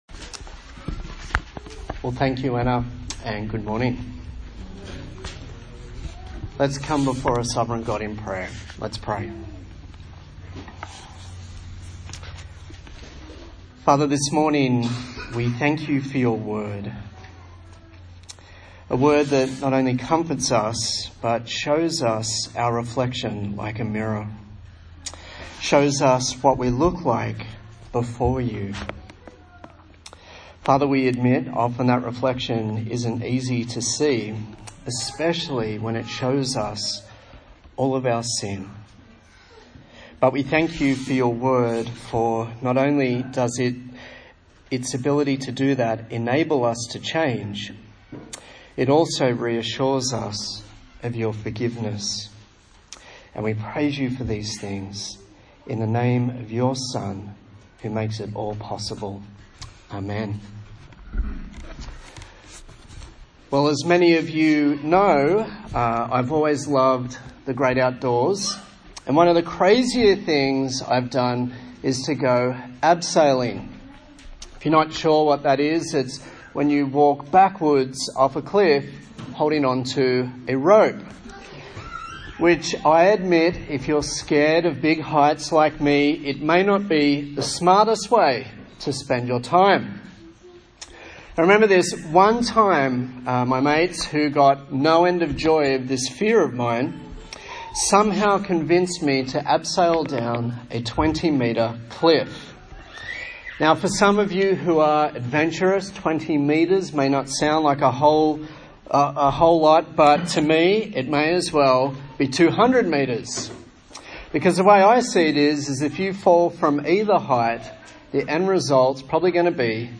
A sermon in the series on the book of 1 Peter
Service Type: Sunday Morning